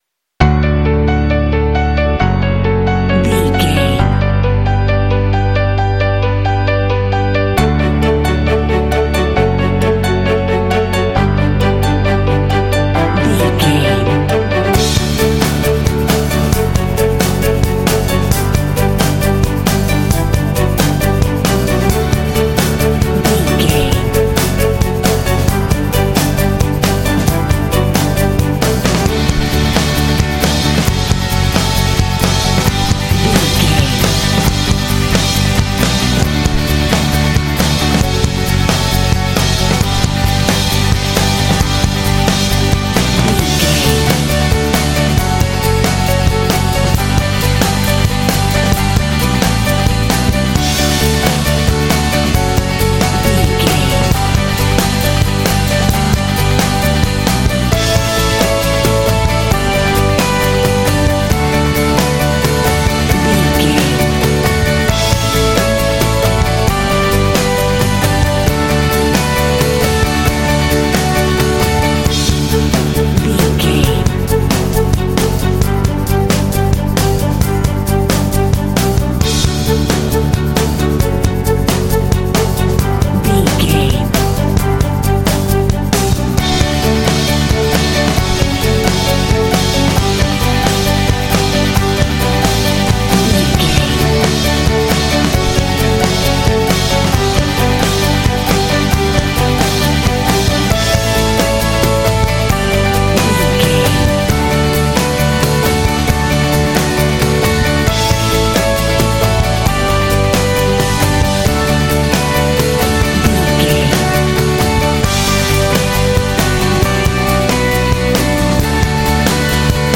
Bright and motivational music with a great uplifting spirit.
In-crescendo
Ionian/Major
epic
uplifting
powerful
strings
orchestra
percussion
piano
drums
rock
contemporary underscore